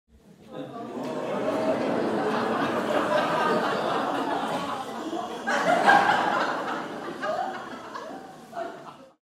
Sound Effect Audience crowd sound effects free download
Sound Effect - Audience crowd laughing - 04